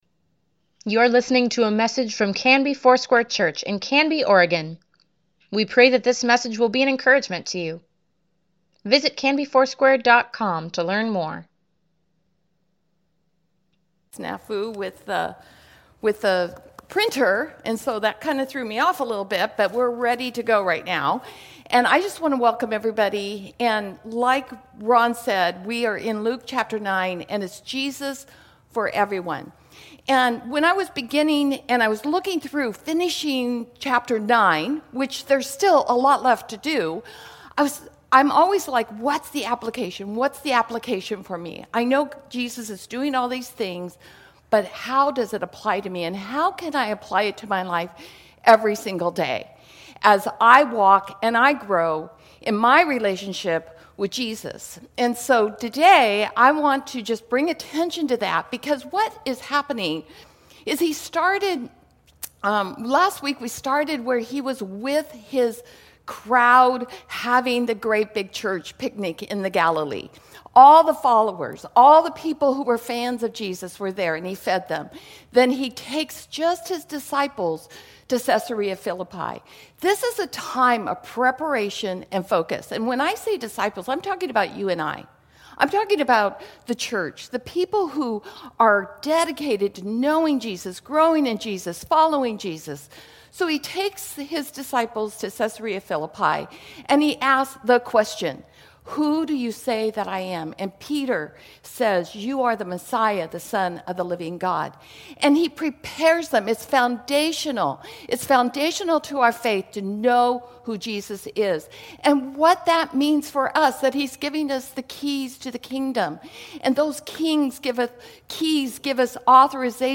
Weekly Email Water Baptism Prayer Events Sermons Give Care for Carus Jesus for Everyone, pt.11 March 14, 2021